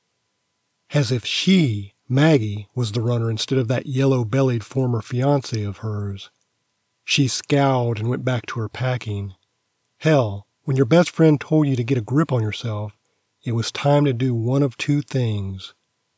Special Interest Groups Audiobook Production
It has the signature 100Hz rolloff to get rid of low pitch rumble and home microphone low pitch sound errors.